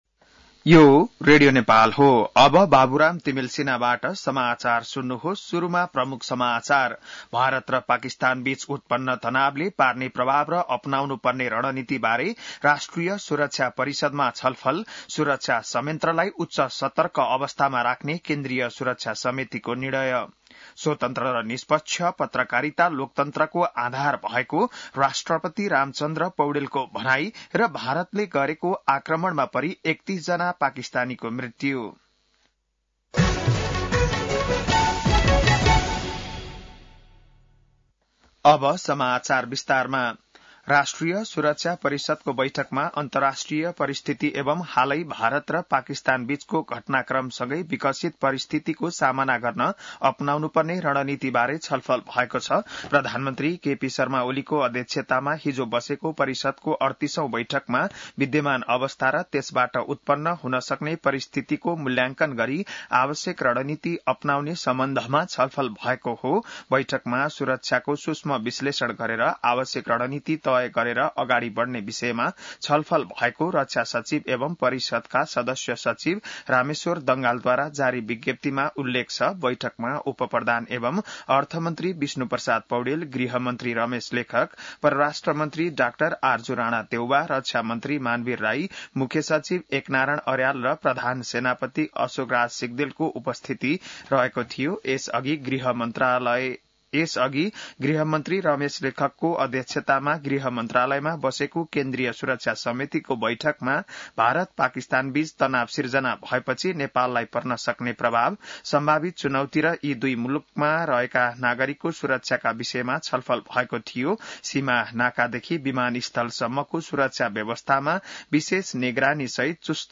बिहान ९ बजेको नेपाली समाचार : २५ वैशाख , २०८२